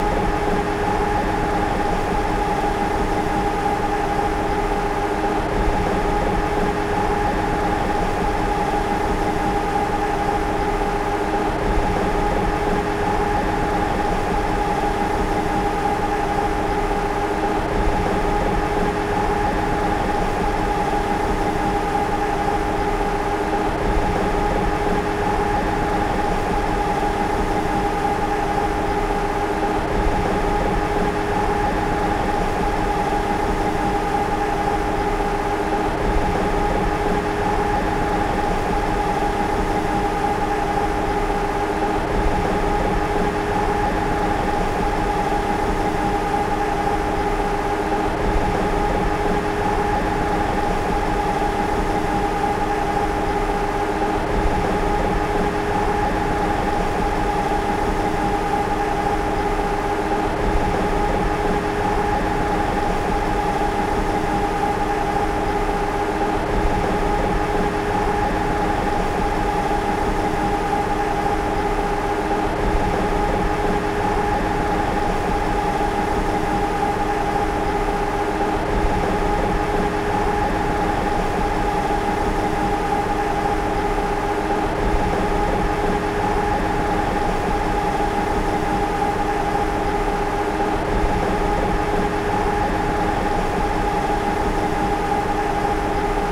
Auto Tire Whine Steady.wav